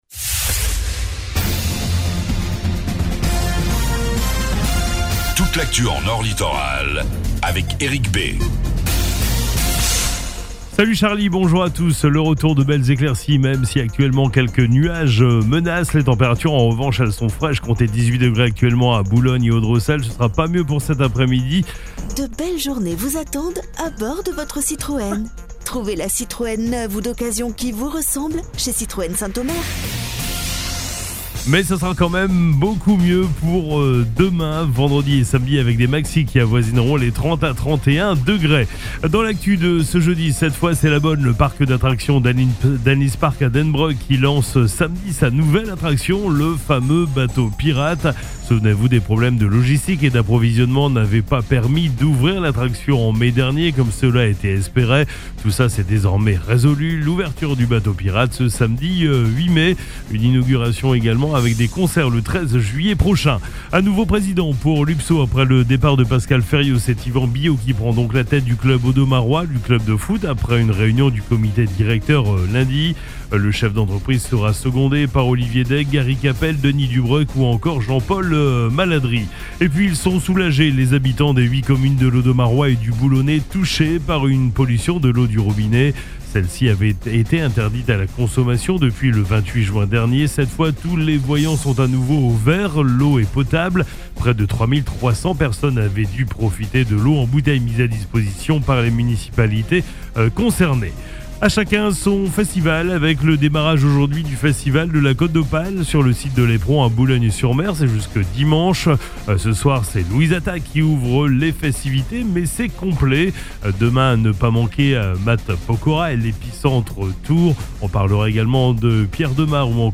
FLASH 06 JUILLET 23